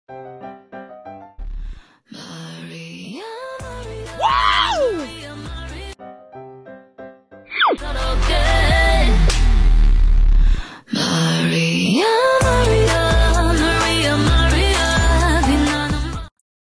Wow 😱 sound effects free download